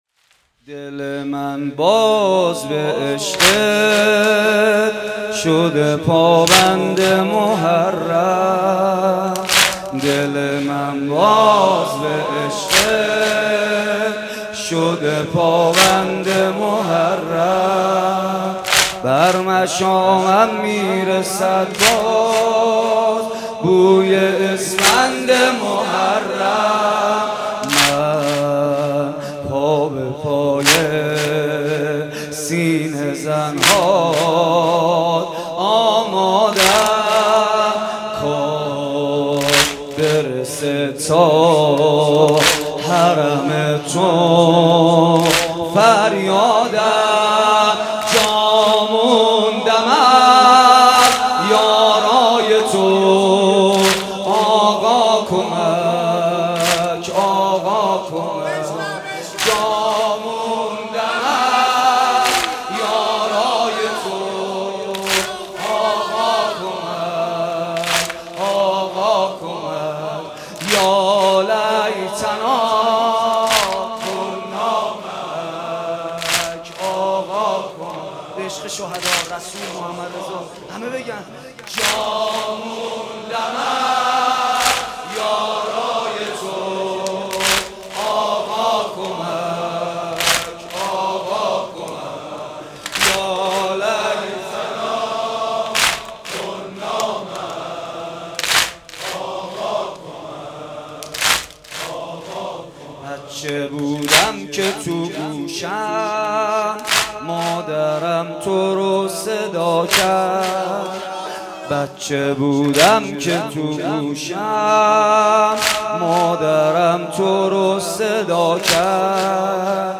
شب چهارم محرم95 /هیئت ریحانه النبی(س)